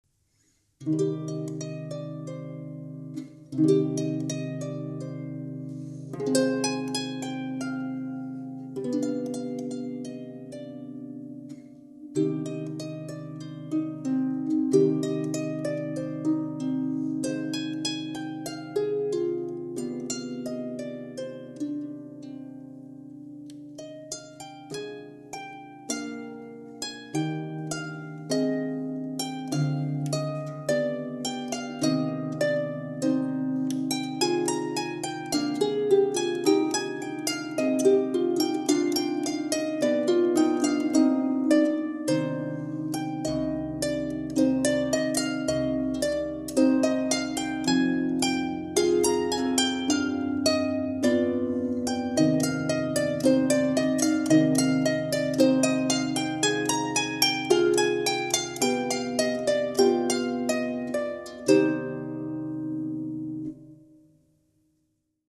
Genere: Classical.